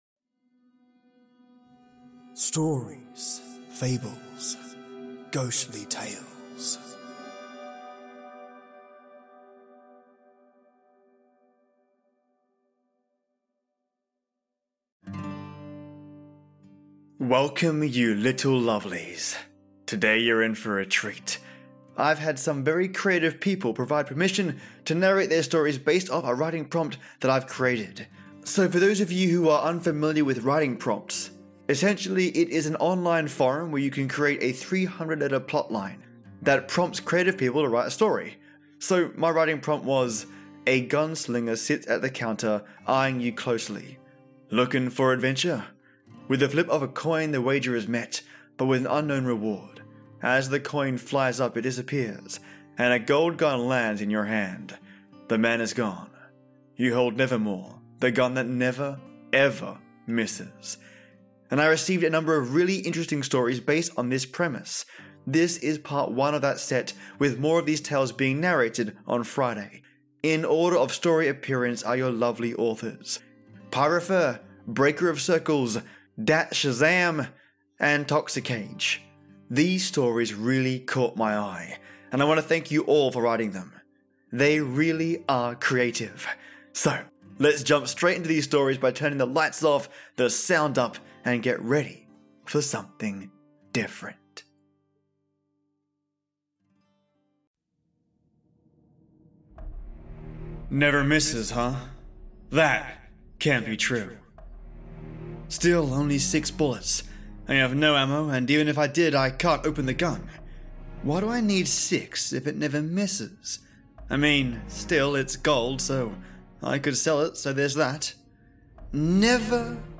Today you’re in for a treat, I’ve had some very creative provide permission to narrate their stories based off a writing prompt that I created.